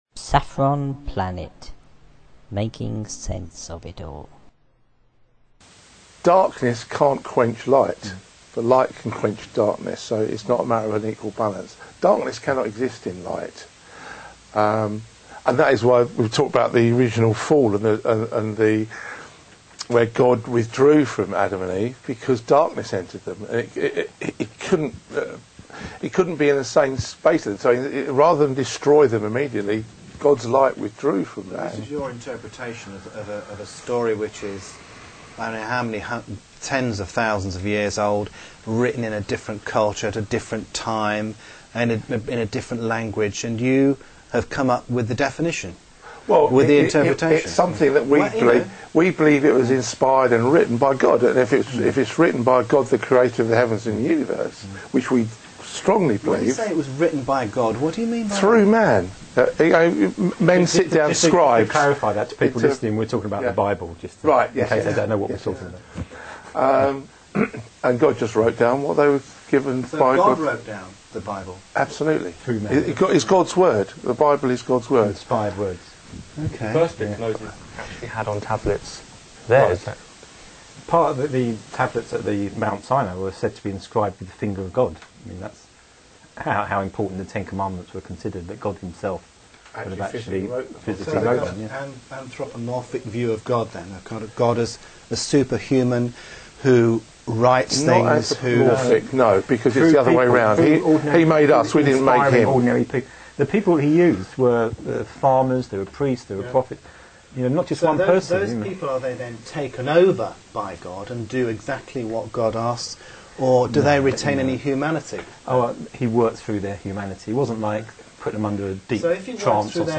christianity_debate.wma